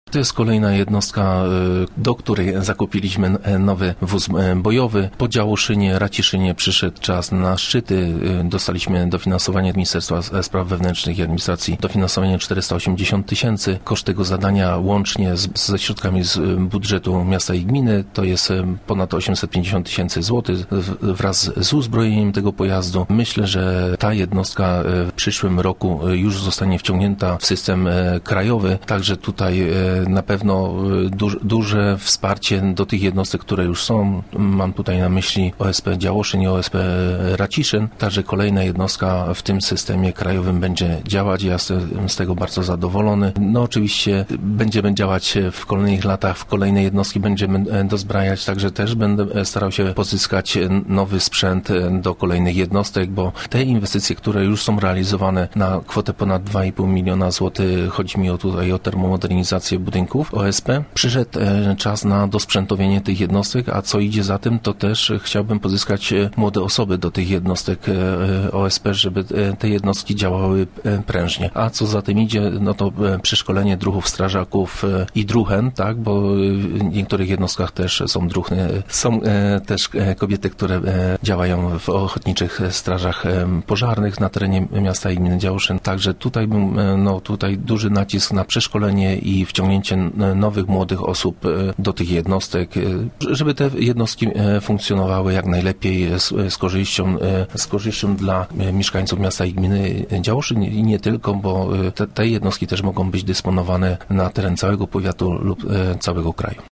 To jest kolejna jednostka, do której zakupiliśmy nowy wóz bojowy – mówi Rafał Drab, burmistrz miasta i gminy Działoszyn.